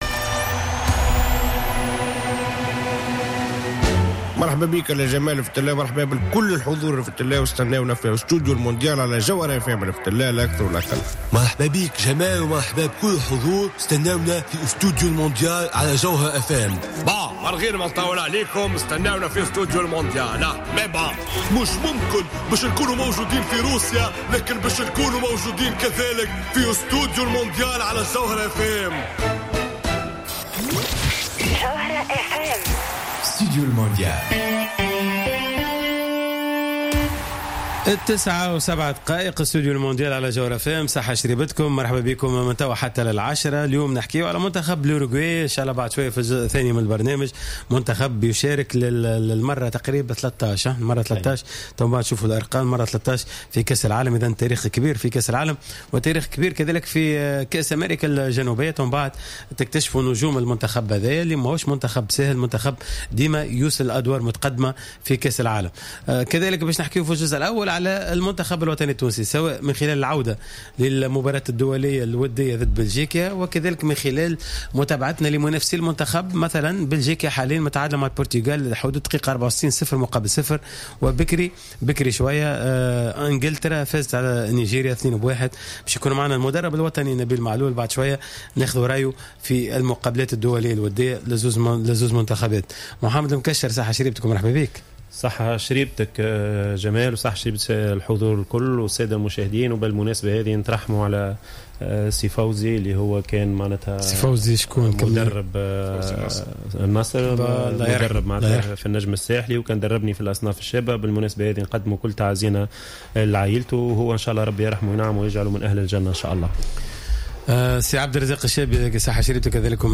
كما تدخل في الجزء الأول من الحصة المدرب الوطني نبيل معلول للحديث حول القائمة النهائية التي إختارها لكأس العالم 2018 بالإضافة لتقييمه لمردود منتخبي إنجلترا و بلجيكا في المقابلات الودية.